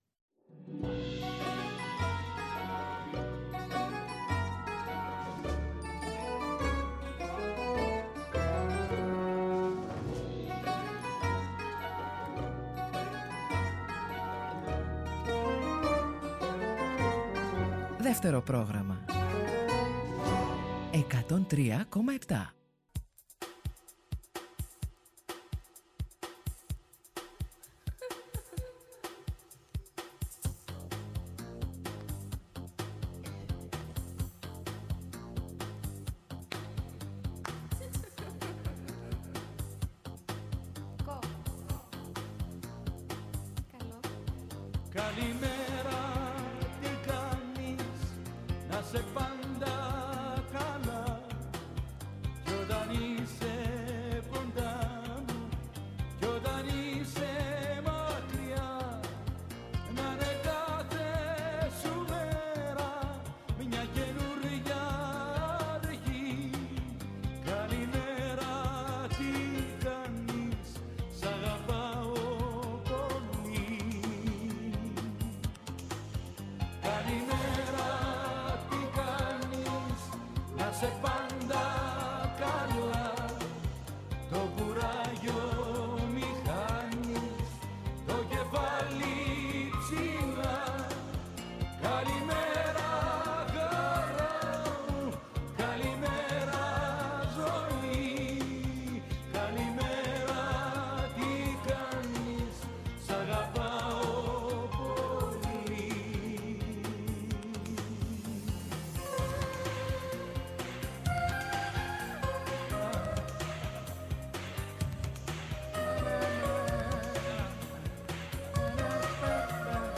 Μουσικές, τραγούδια, θετικές σκέψεις, χρηστικές και χρήσιμες ειδήσεις, χαρούμενη γνώση που έλεγε και ο Φρίντριχ Νίτσε στο ομώνυμο βιβλίο του και φυσικά καλή διάθεση.